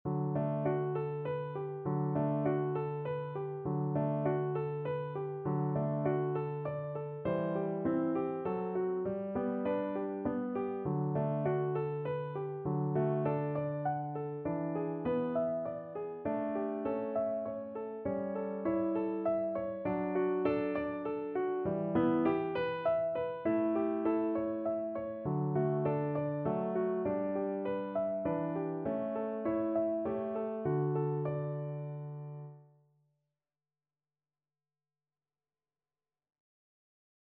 Play (or use space bar on your keyboard) Pause Music Playalong - Piano Accompaniment Playalong Band Accompaniment not yet available reset tempo print settings full screen
17th-century English folk song.
D major (Sounding Pitch) (View more D major Music for Violin )
3/4 (View more 3/4 Music)
Moderato